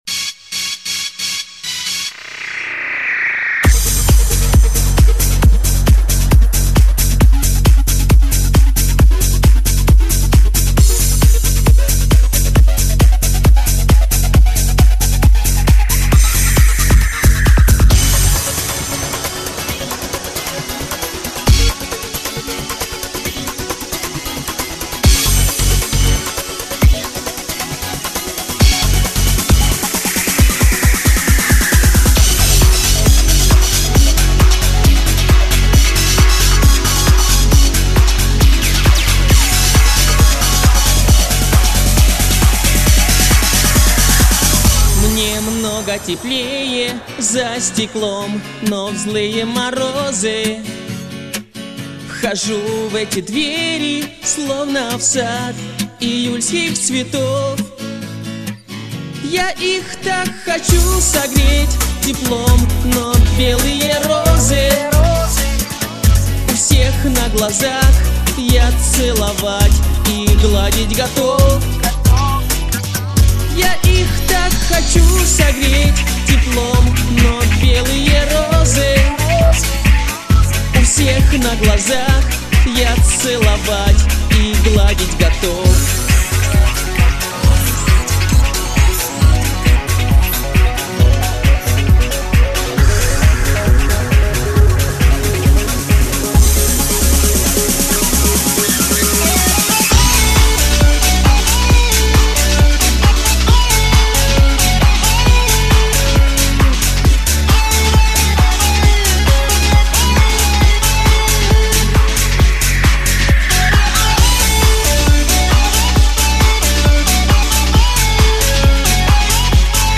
сильно выделяются верха, - что заметно засоряет фонограмму.